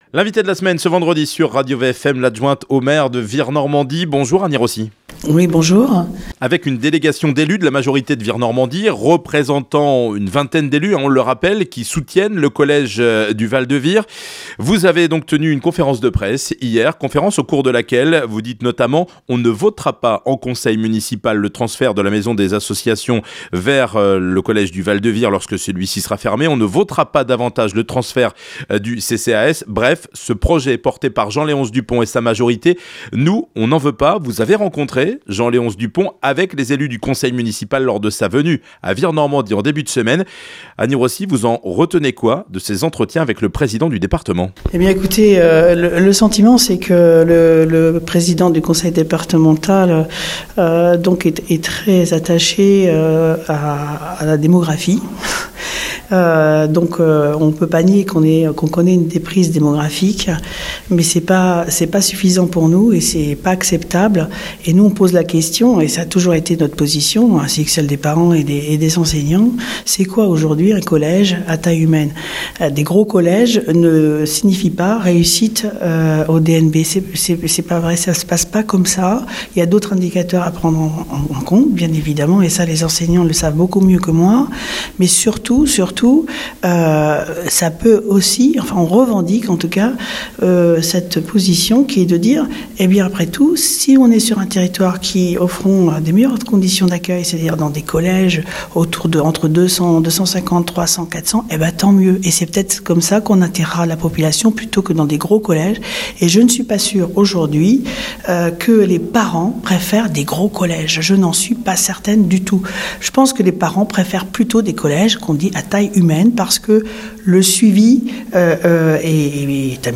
Annie Rossi L'Invité de la semaine sur RadioVFM, Annie Rossi, Adjointe au Maire de Vire Normandie déléguée aux finances et aux marchés publics.